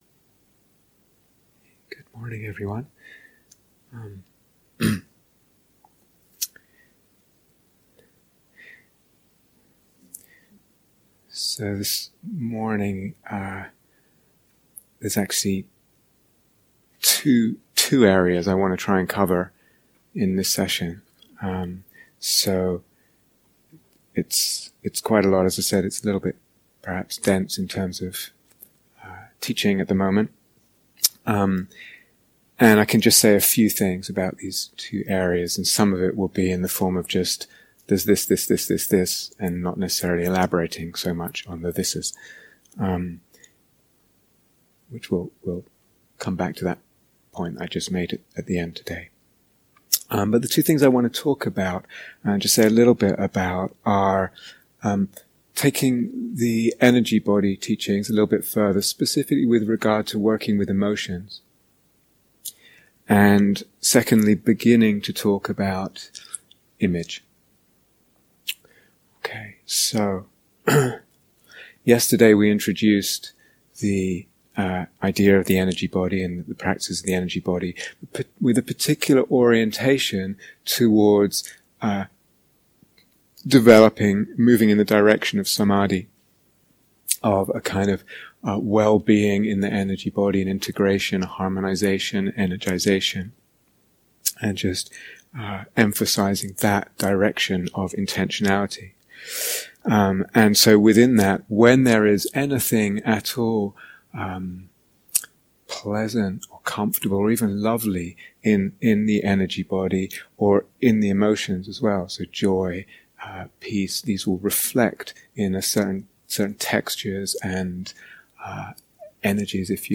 Please Note: This series of teachings is from a retreat for experienced practitioners